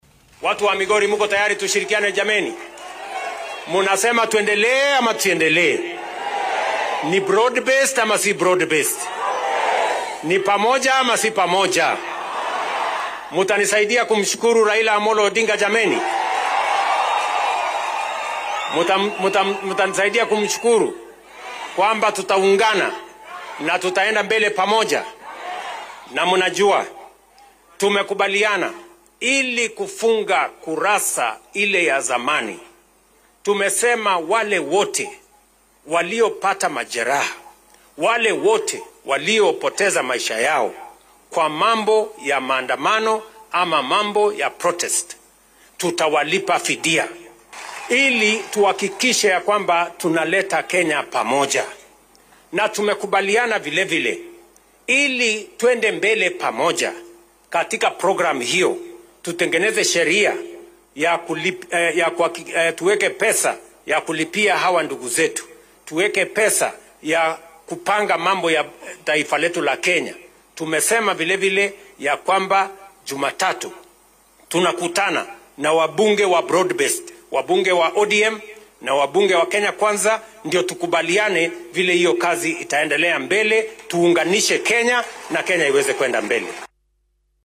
DHEGEYSO:Madaxweynaha dalka oo ka hadlay magdhowga ay dowladdu bixinaysa